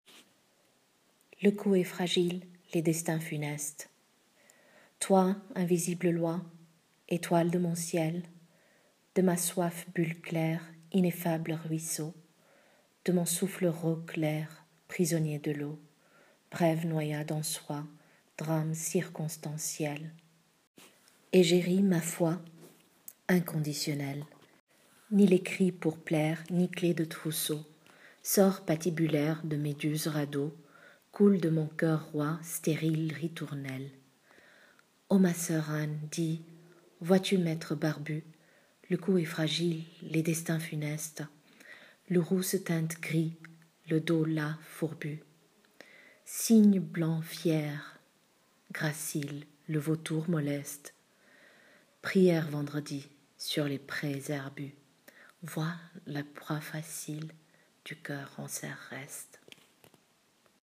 Lecture du poème